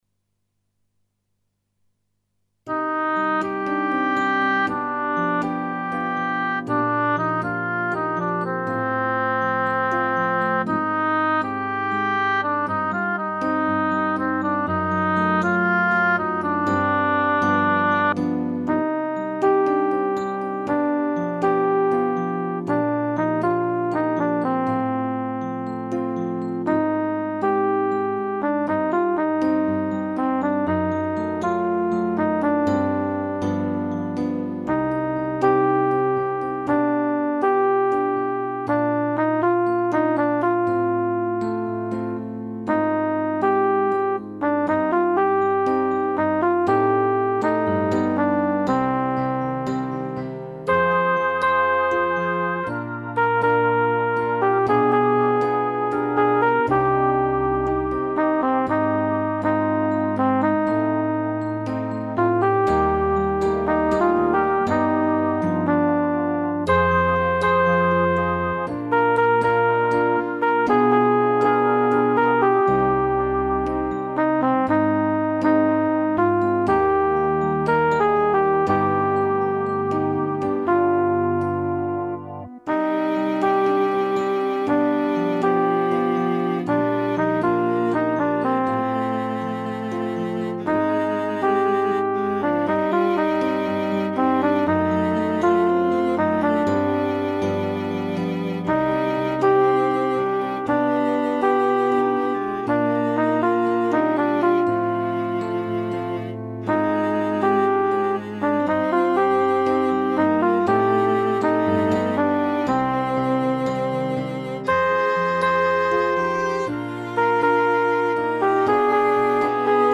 pour choeur de femmes à trois voix
fichier de travail pour la voix lead